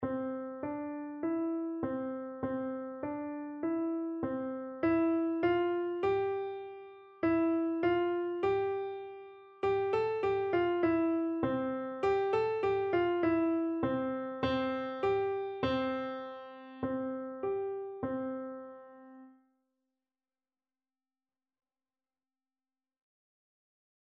Traditional Trad. Frere Jacques Piano version
C major (Sounding Pitch) (View more C major Music for Piano )
4/4 (View more 4/4 Music)
Piano  (View more Beginners Piano Music)
Traditional (View more Traditional Piano Music)